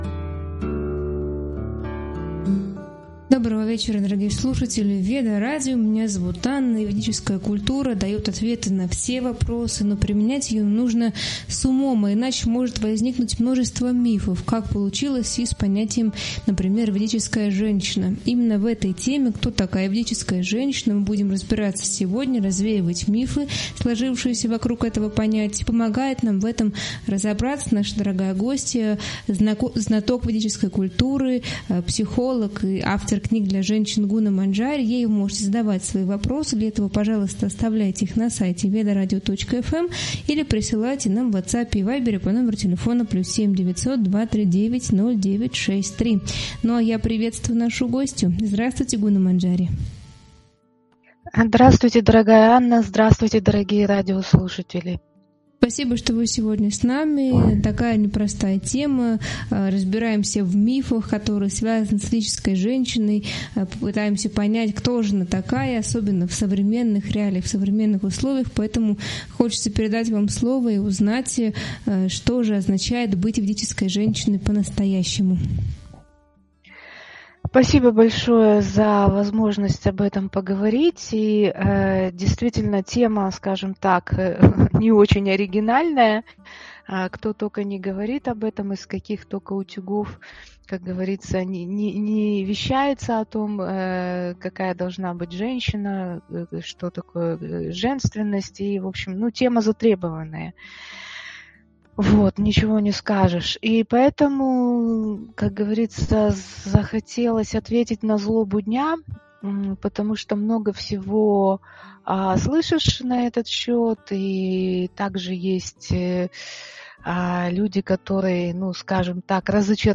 Гостем является знаток водической культуры и психолог.